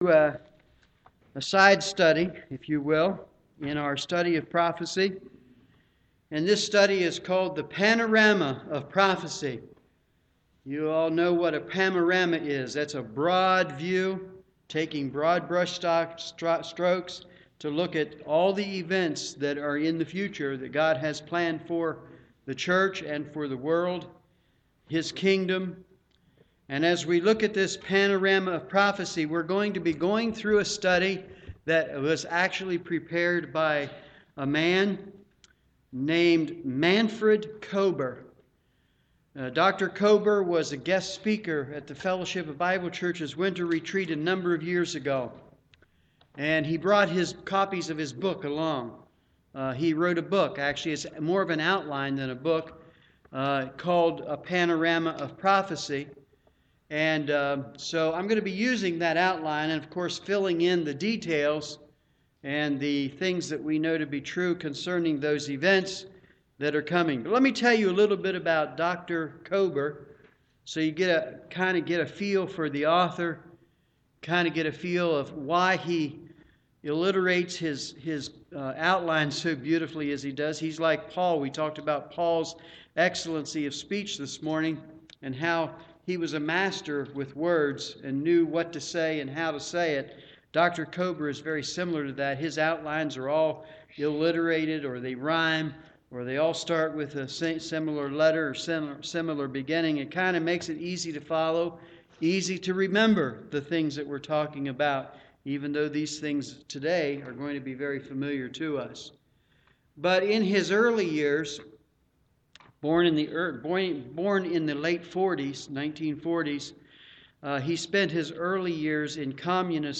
Evening Service
Sermon